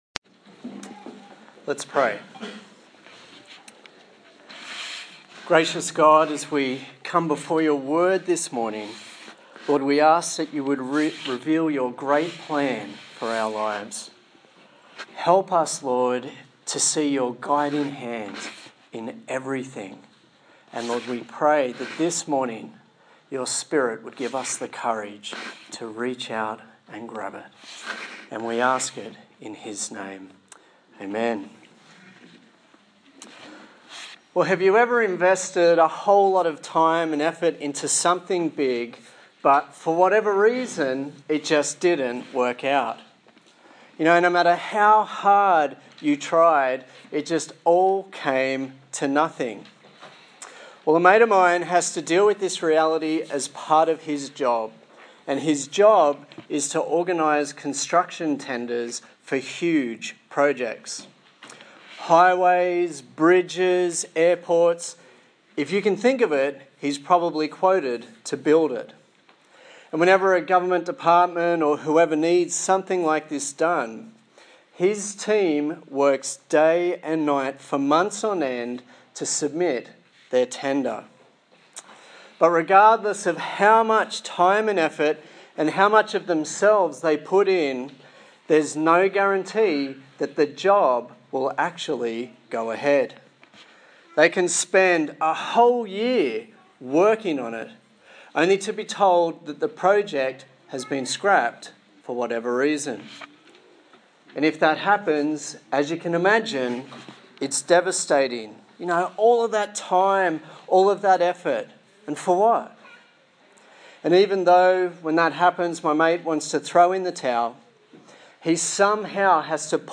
1 Samuel Passage: 1 Samuel 16 Service Type: Sunday Morning